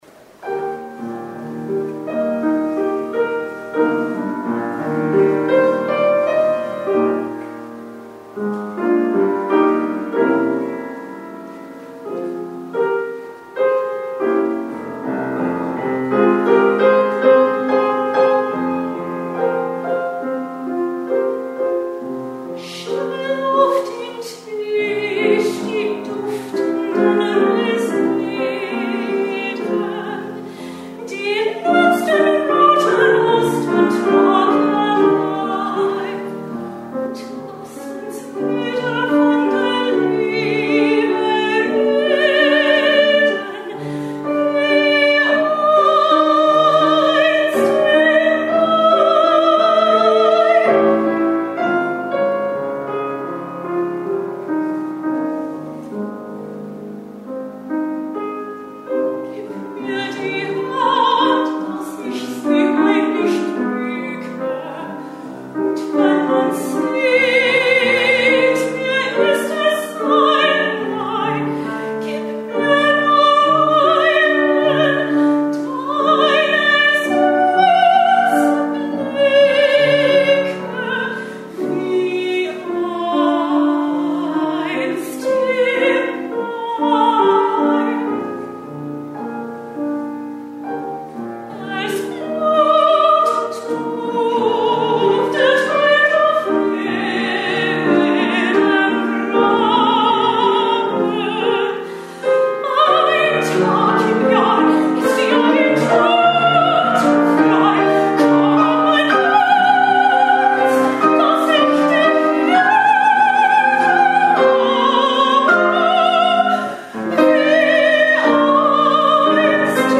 at a recent concert in Palm Beach, Florida.
Collaborative pianist